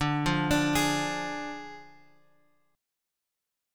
Ddim chord {x 5 3 x 3 4} chord
D-Diminished-D-x,5,3,x,3,4-8.m4a